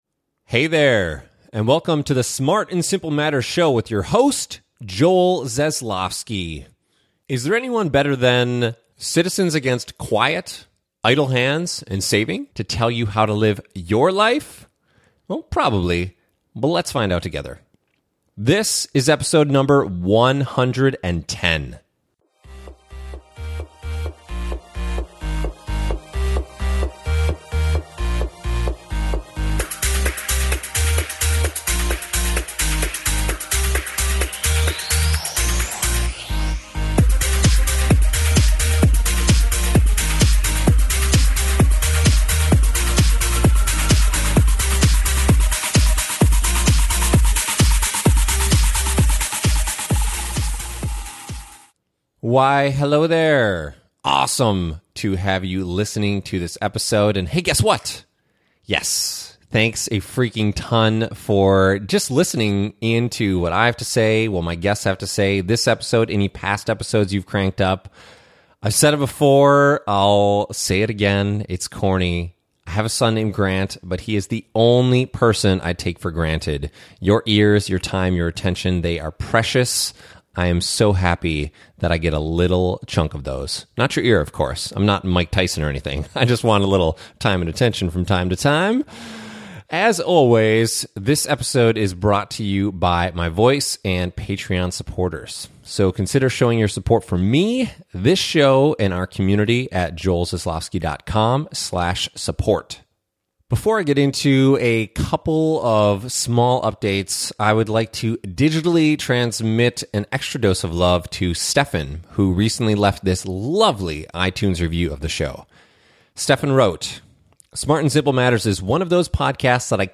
This special solo episode features what the food industry is rubbing your nose in (often successfully), what Citizens against Quiet, Idle Hands, and Saving needs you to know, and some eye-opening stats about how much stuff Americans actually own.